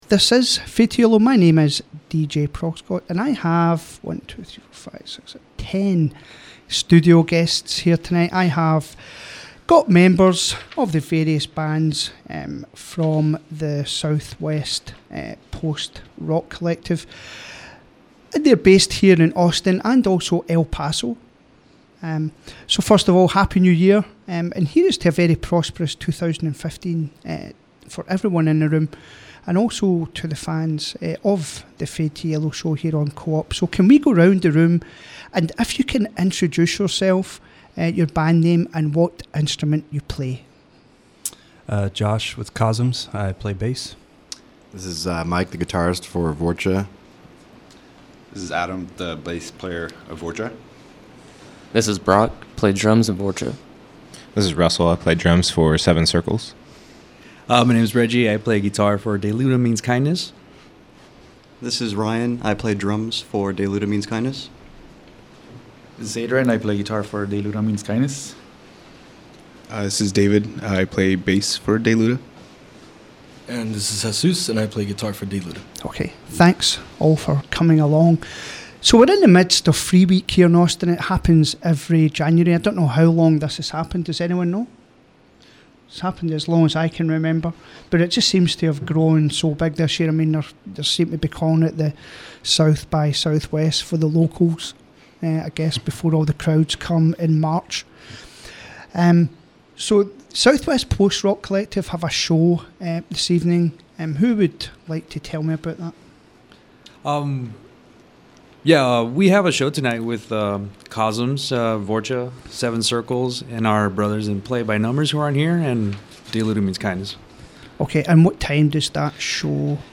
This is an interview that was done with some of the bands that make up the Southwest Post Rock Collective. It was just before a ‘free week’ show at the historic Scoot Inn here in Austin, Texas. Bands present were Cosms, Vorcha, Seven Circles, Dayluta Means Kindness.
There is also a 5 minute montage of sound featuring the sounds of each band contained within the interview.